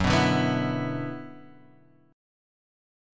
F#7sus4#5 chord {2 2 0 x 0 0} chord